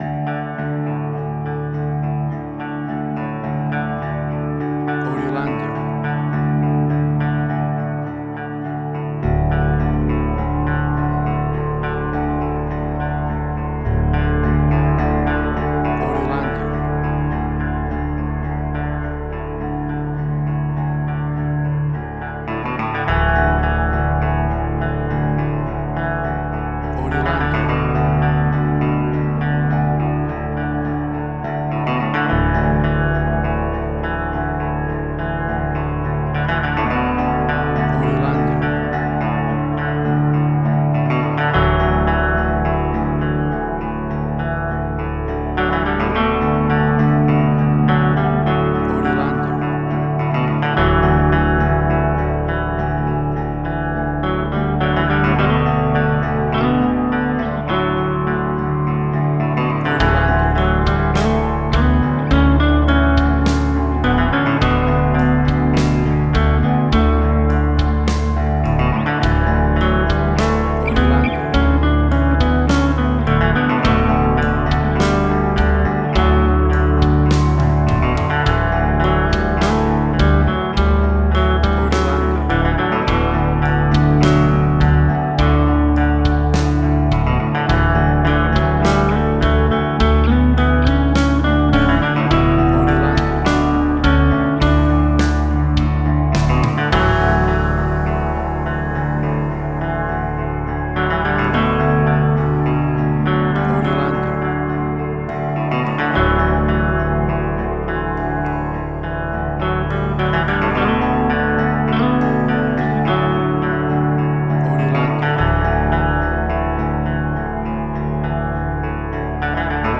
Hard Rock 102.jpg
Heavy Metal.
Tempo (BPM): 52